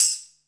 tambourine6.wav